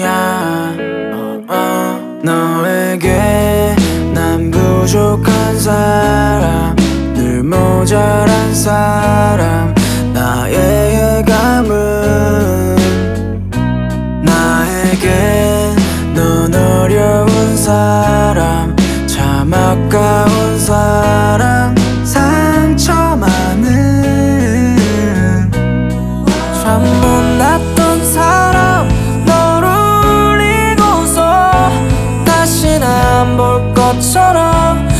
# Korean Rock